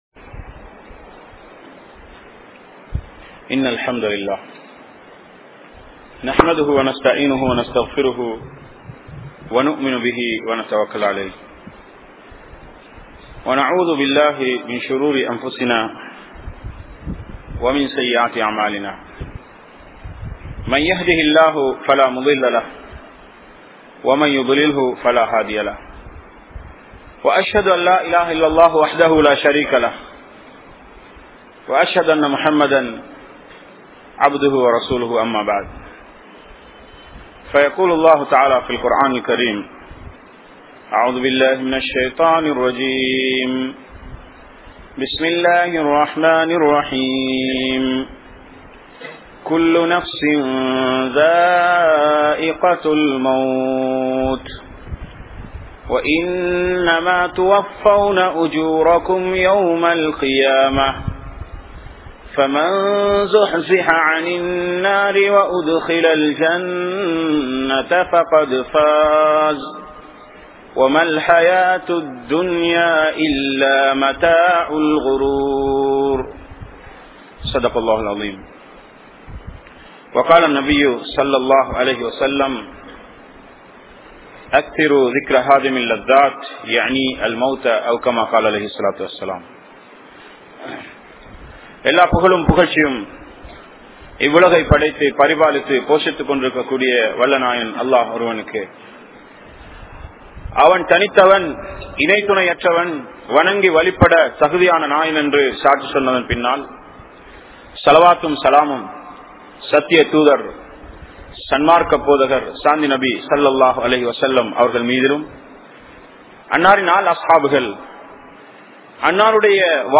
Maranam (மரணம்) | Audio Bayans | All Ceylon Muslim Youth Community | Addalaichenai